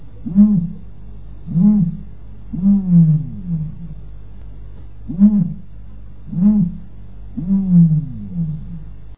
На этой странице вы найдете подборку звуков страуса – от характерного шипения до необычных гортанных криков.
Страус кричит и издает необычный звук